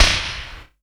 SMASH.wav